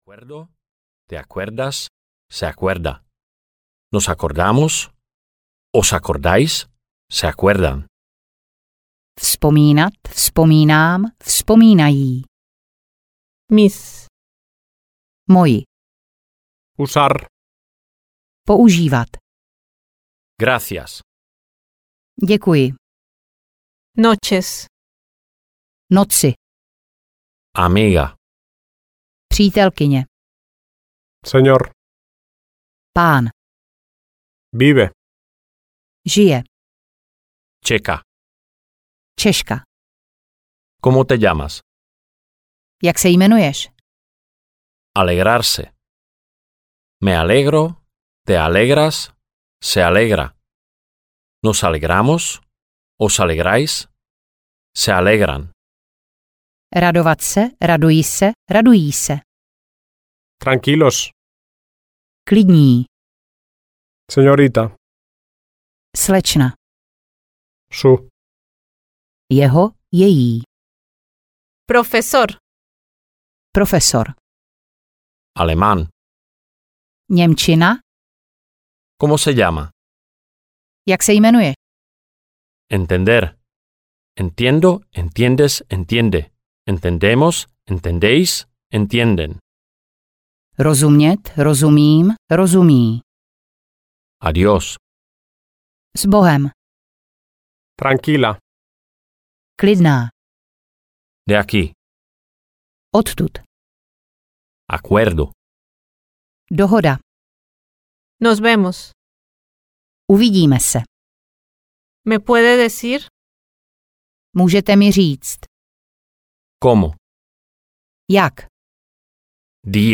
Audio knihaŠpanělská slovíčka A1, A2
Ukázka z knihy